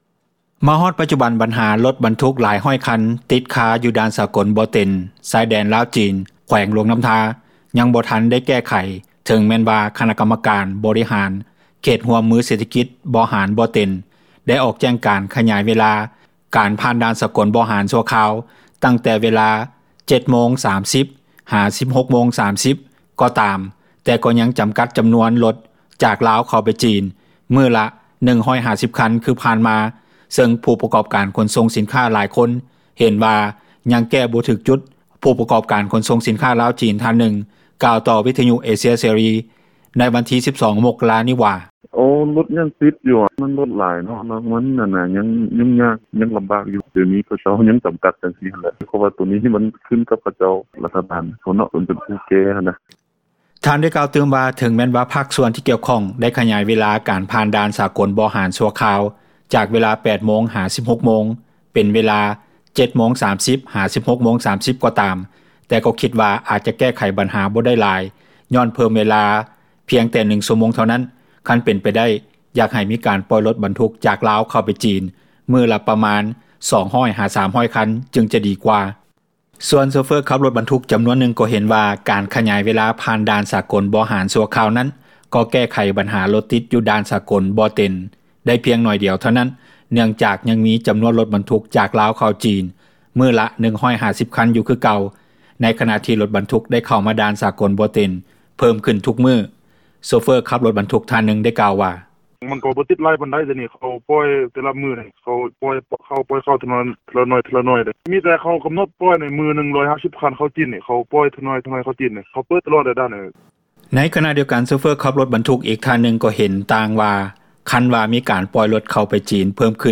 ໂຊເຟີ້ຂັບຣົຖບັນທຸກທ່ານນຶ່ງກ່າວວ່າ:
ໂຊເຟີຂັບຣົຖບັນທຸກອີກທ່ານນຶ່ງກ່າວວ່າ: